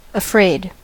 afraid: Wikimedia Commons US English Pronunciations
En-us-afraid.WAV